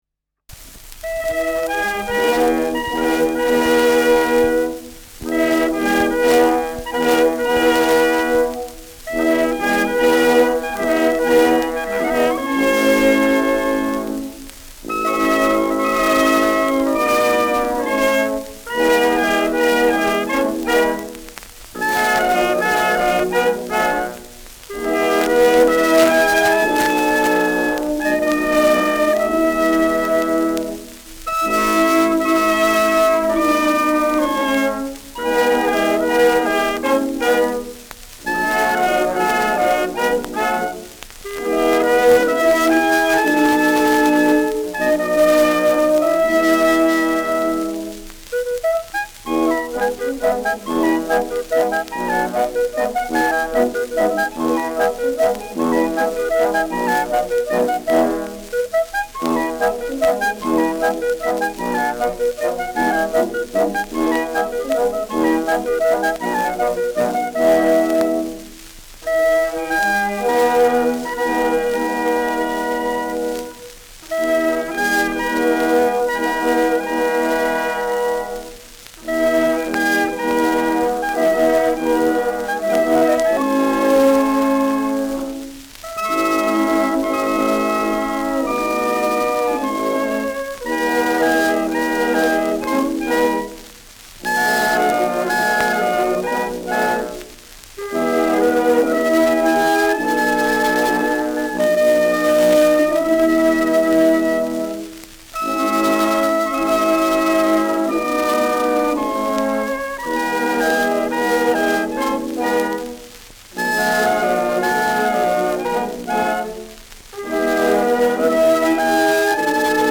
Schellackplatte
leichtes Rauschen : präsentes Knistern : leichtes „Schnarren“ : abgespielt : leiert